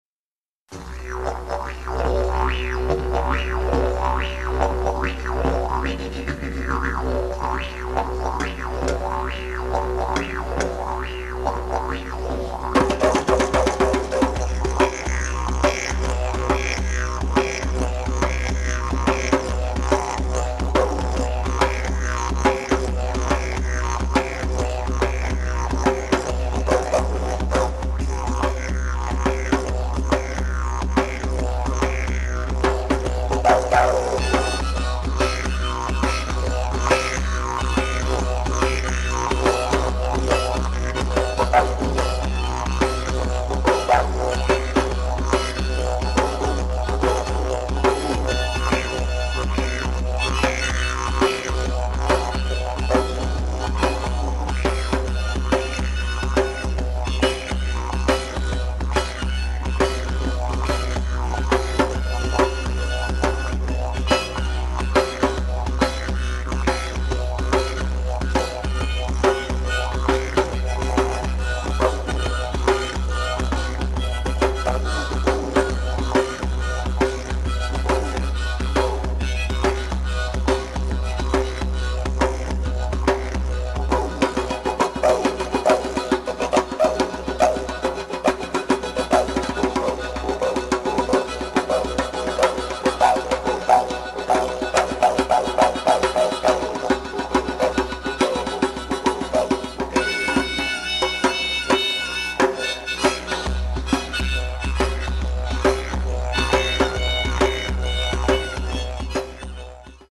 Auftritt am Freitag, 6. Juli 2001 am Swizzeridoo um 20.00 Uhr
Didgeridoo, Indianer-Flöte, Blues Harp, Gitarre und Percussion (Djembe, Ocean Drum, Clap Sticks)
Soundfiles vom Konzert: